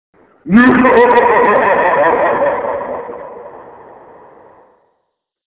Mwhahahaa.mp3